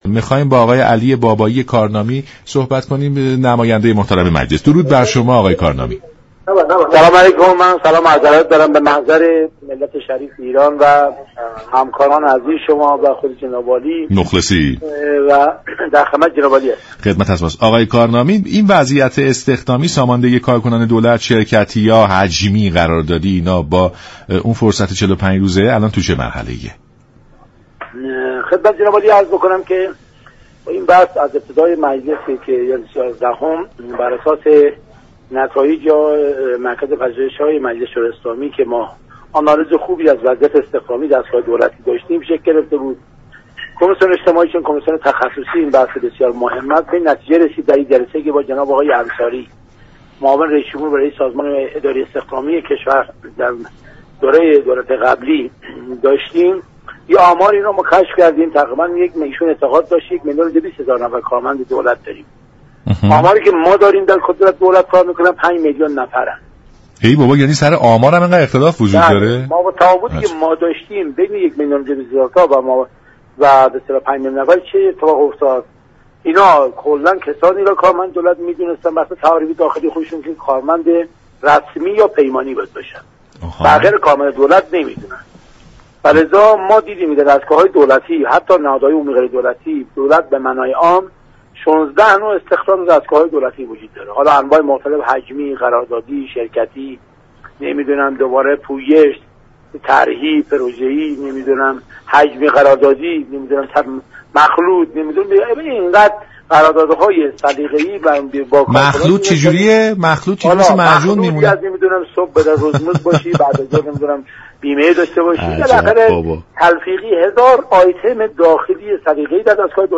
به گزارش شبكه رادیویی ایران، «علی بابایی كارنامی» نایب رئیس كمیسیون اجتماعی مجلس شورای اسلامی، در برنامه «سلام صبح بخیر» رادیو ایران به مهلت 45 روزه مجلس به دولت برای ساماندهی كاركنان دولتی اشاره كرد و گفت: در طرح ساماندهی كاركنان دولت، تمامی فعالیت های كاری در ادارات دولت در دو قالب استخدام رسمی و قراردادی تعریف می شود.